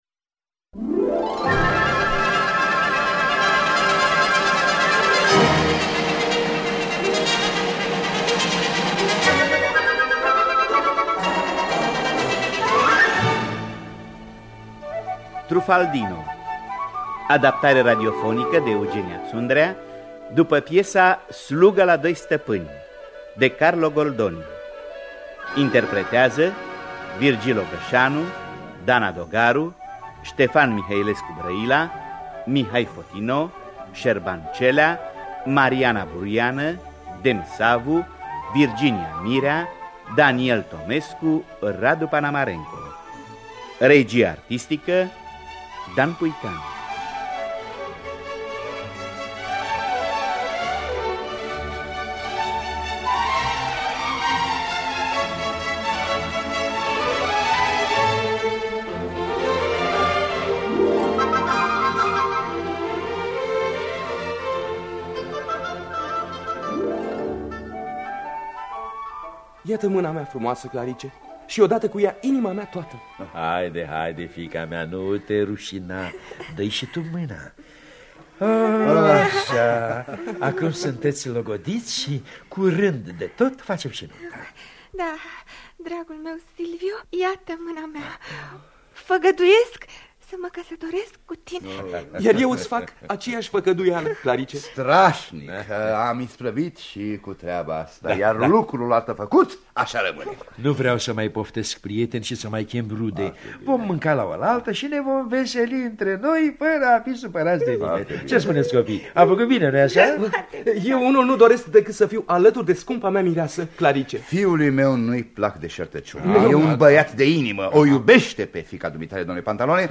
Truffaldino de Carlo Goldoni – Teatru Radiofonic Online
Adaptarea radiofonică de Eugenia Ţundrea.